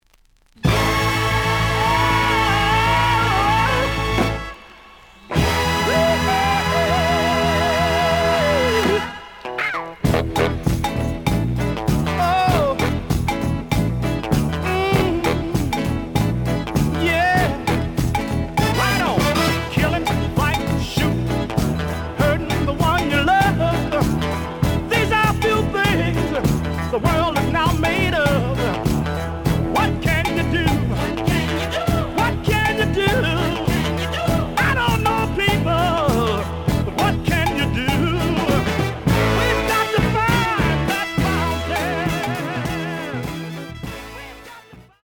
試聴は実際のレコードから録音しています。
The audio sample is recorded from the actual item.
●Genre: Soul, 70's Soul